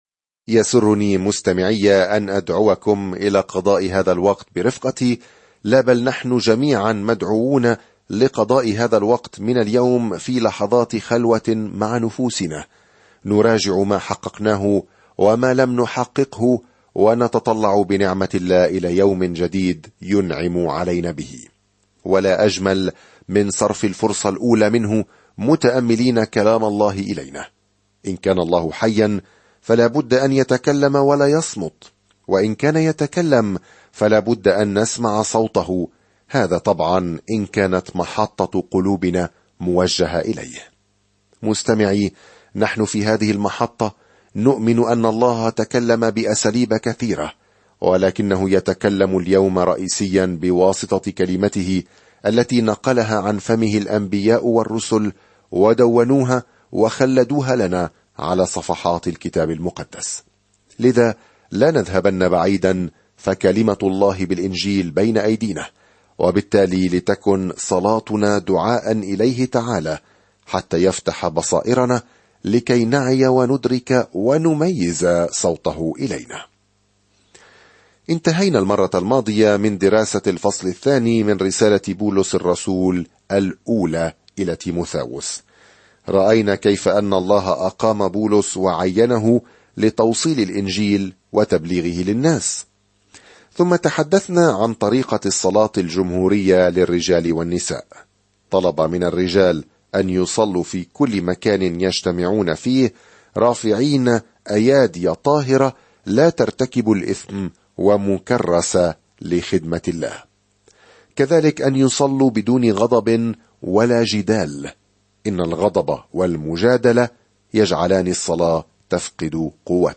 الكلمة تِيمُوثَاوُسَ ٱلْأُولَى 1:3-8 يوم 6 ابدأ هذه الخطة يوم 8 عن هذه الخطة توفر الرسالة الأولى إلى تيموثاوس إشارات عملية على أن شخصًا ما قد تغير بعلامات التقوى الإنجيلية الحقيقية. سافر يوميًا عبر رسالة تيموثاوس الأولى وأنت تستمع إلى الدراسة الصوتية وتقرأ آيات مختارة من كلمة الله.